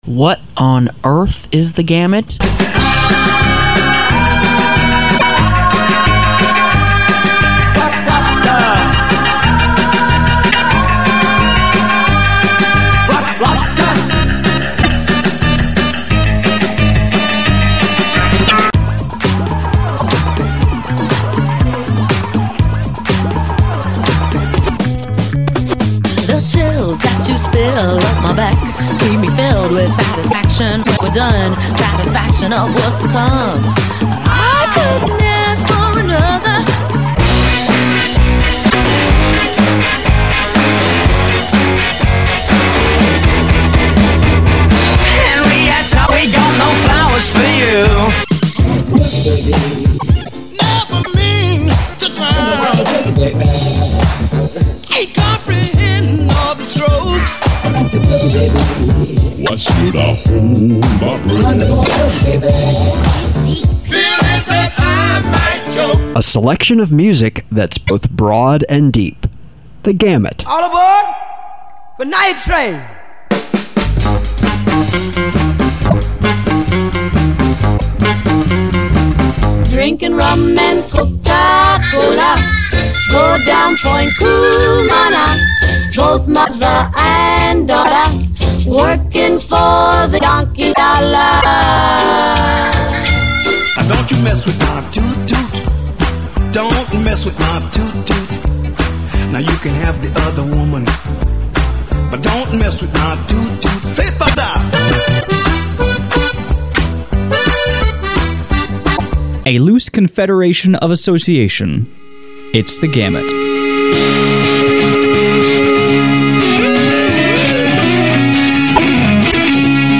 World/Variety World/Variety More Info Close